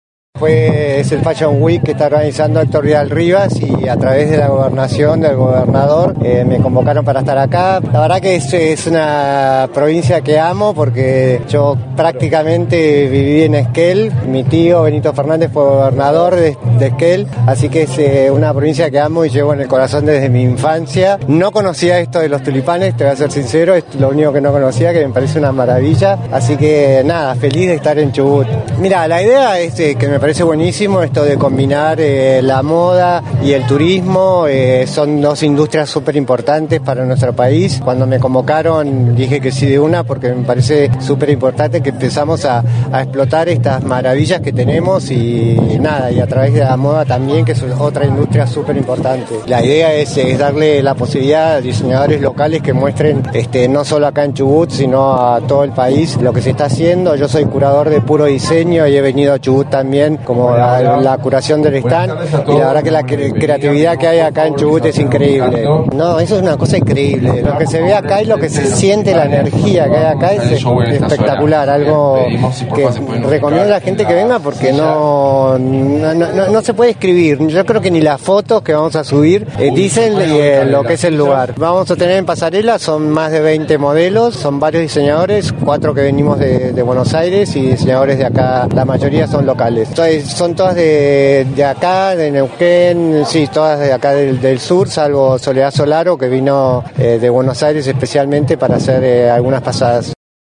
El diseñador Benito Fernandez participó el fin de semana del evento denominado Fashion Week en el Campo de Tulipanes. En diálogo con la prensa manifestó sentirse en parte esquelense, ya que tiene familiares en la ciudad y recordó que su tío Benito Fernandez fue gobernador de la provincia.